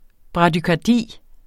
Udtale [ bʁɑdykɑˈdiˀ ]